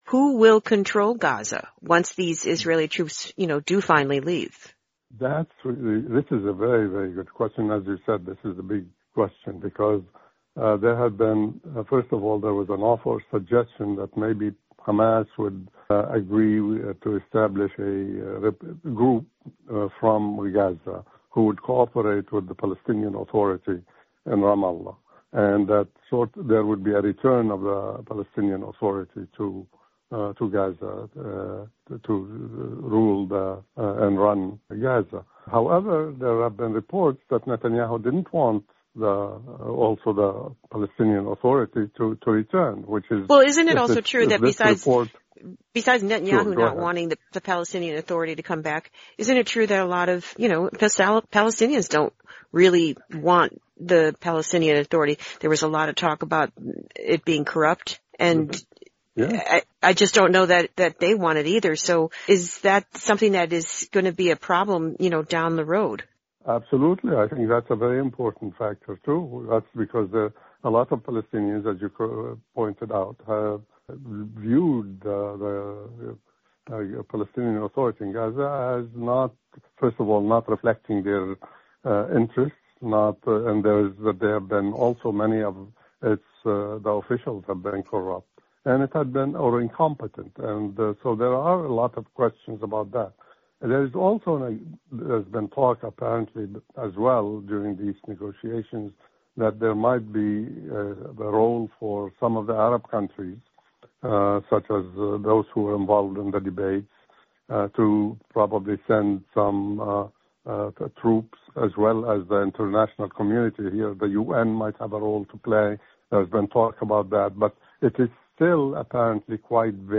Lebanese-American analyst looks at what comes next in Gaza